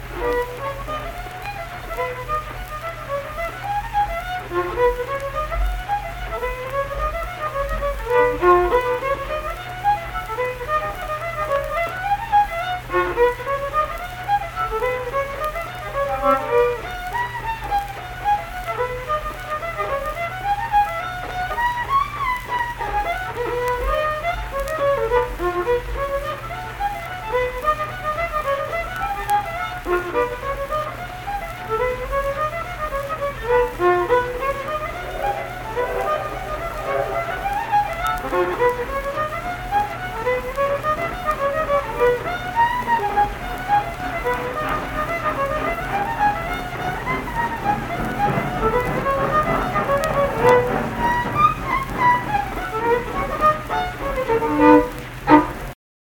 Unaccompanied fiddle music
Verse-refrain 2(2).
Instrumental Music
Fiddle
Pleasants County (W. Va.), Saint Marys (W. Va.)